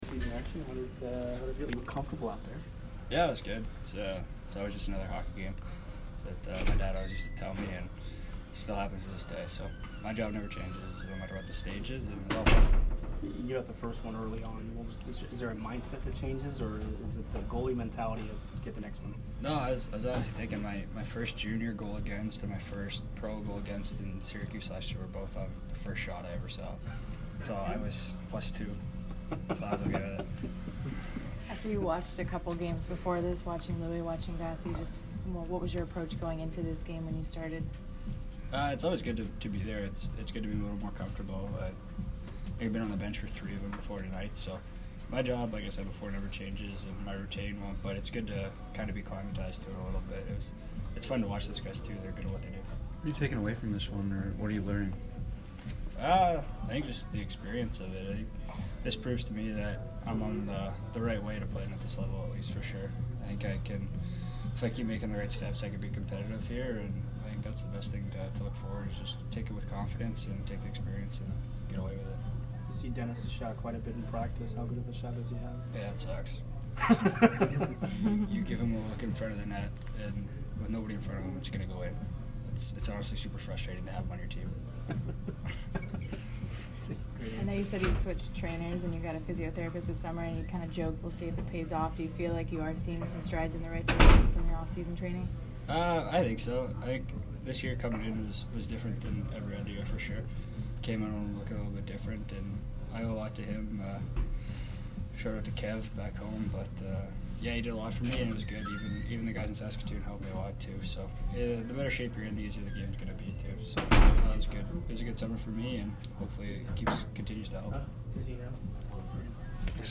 Connor Ingram post-game 9/22